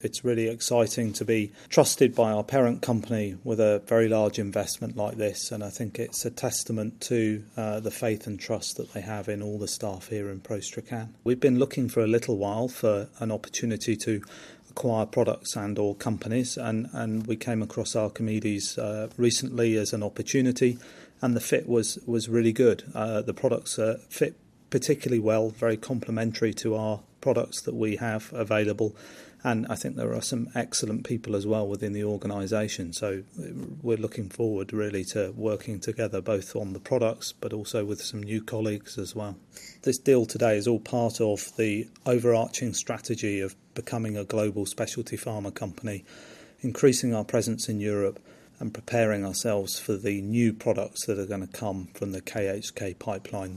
speaks to Radio Borders News...